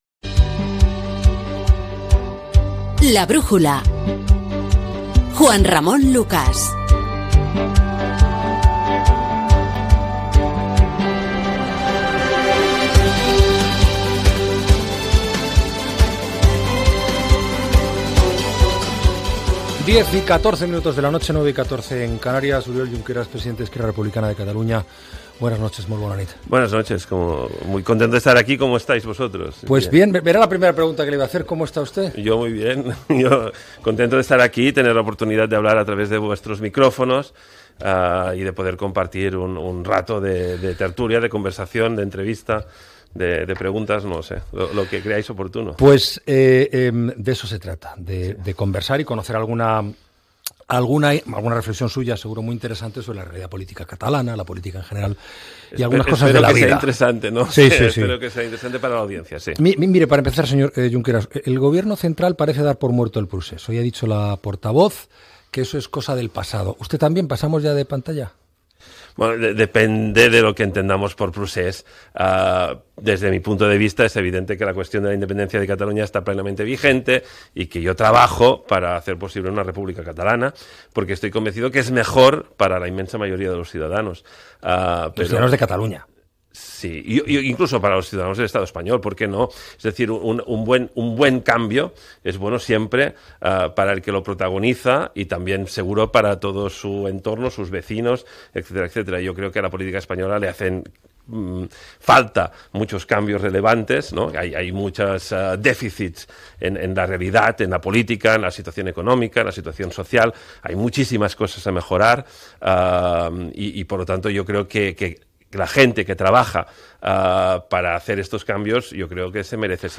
Careta del programa, hora, entrevista al polític Oriol Junqueras, president d'Esquerra Republicana de Catalunya, sobre si el procés independentista de Catalunya ja s'ha acabat
Informatiu